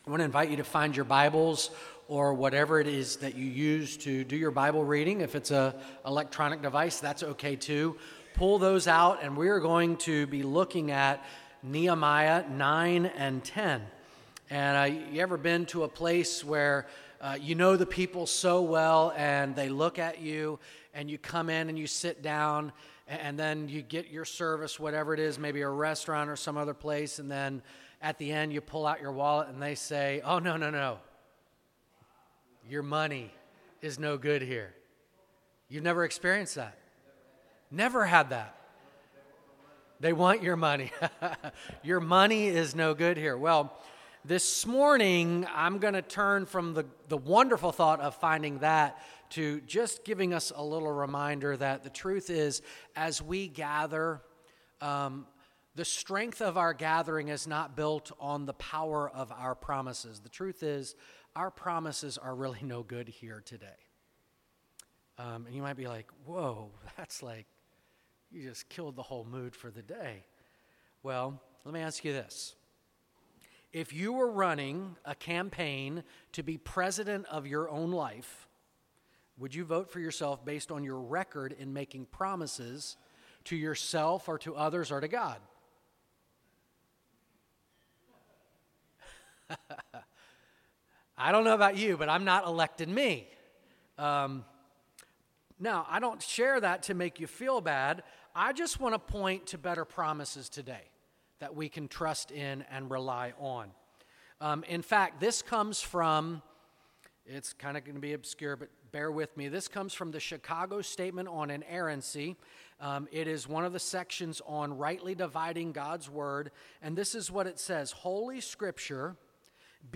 Sermons | Great Commission Baptist Church